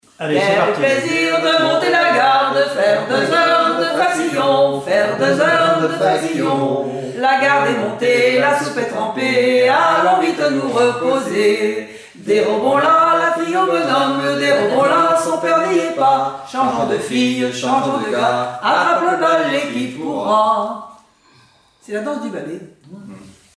Chansons et formulettes enfantines
Pièce musicale inédite